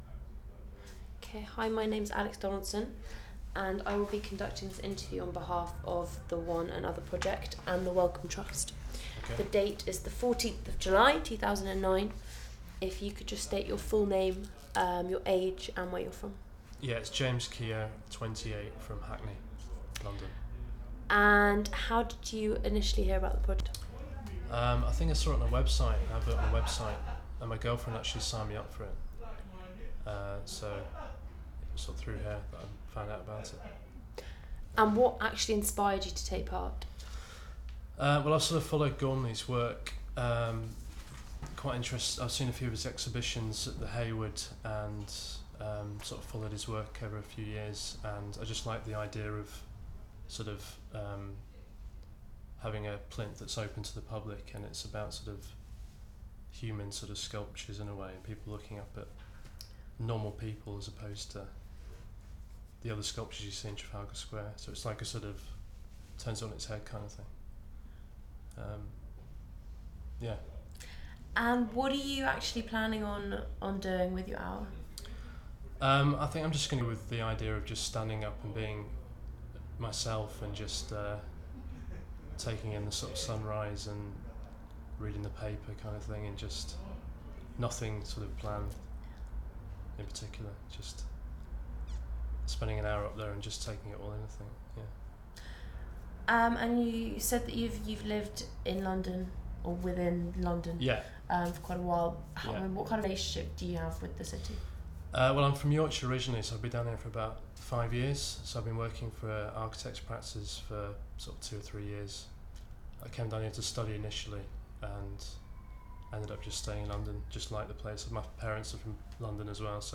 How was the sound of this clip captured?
Audio file duration: 00:07:19 Format of original recording: wav 44.1 khz 16 bit MicportPro.